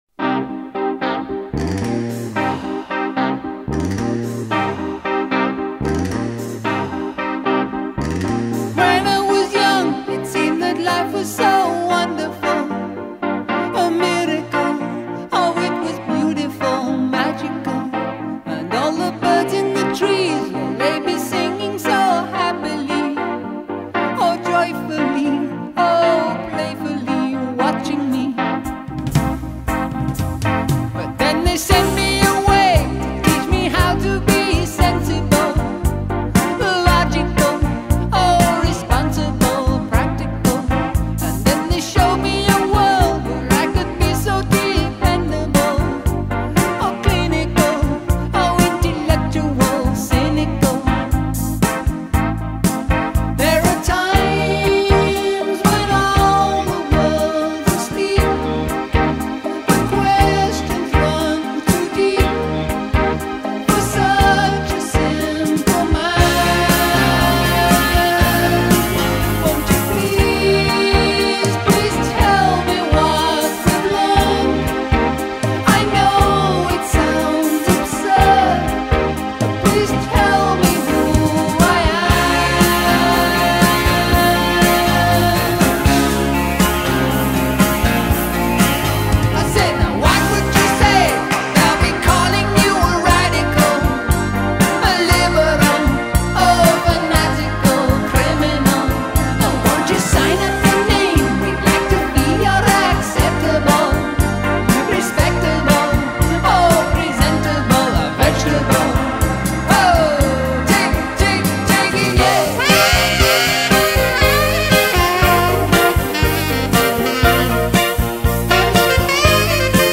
Fragmento de audio del Programa radiofónico 'La rosa de los vientos'